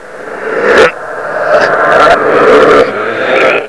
deadsignal3.wav